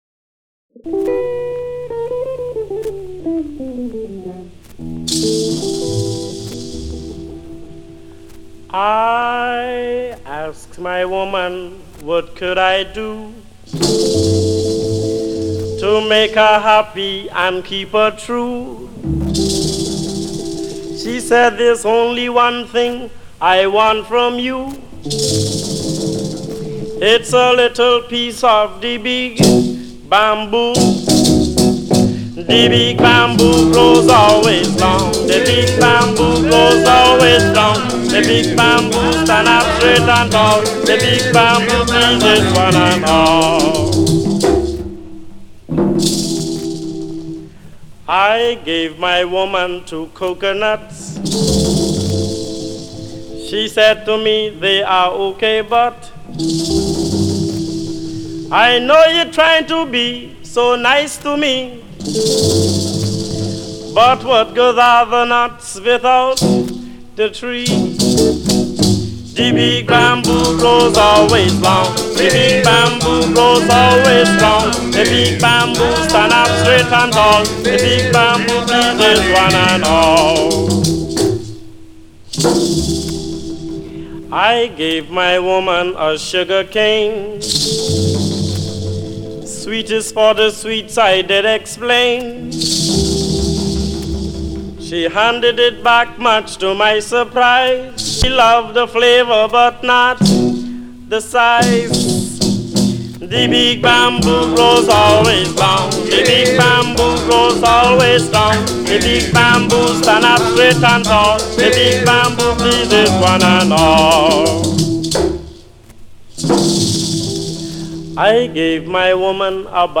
Live at the Windsor Inne’s [sic] BA-MA Room
vocalist